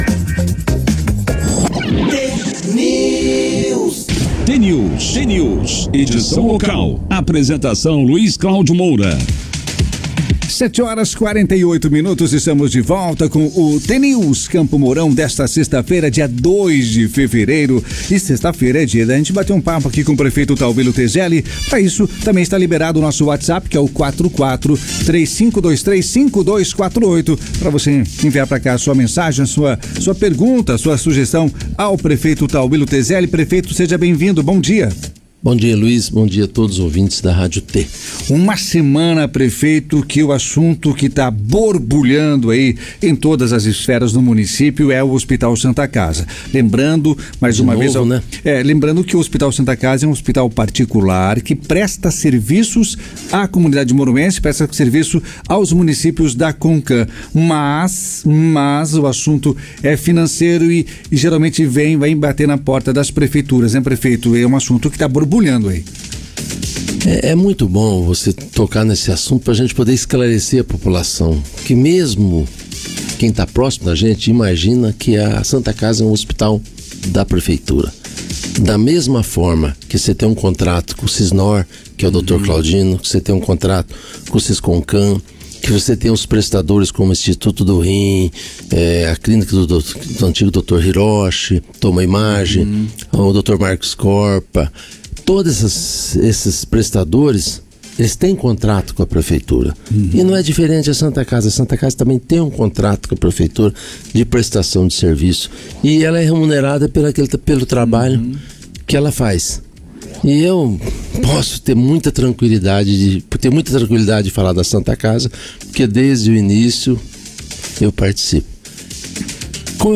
Prefeito Tauillo na Rádio T FM.
O jornal T News, da Rádio T FM, como acontece há mais de duas décadas, contou na edição desta sexta-feira, dia 2, com a participação de Tauillo Tezelli, atual prefeito de Campo Mourão.